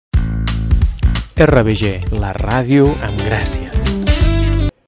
Indicatiu de la ràdio
Ràdio per Internet de l'Escola Vedruna de Gràcia.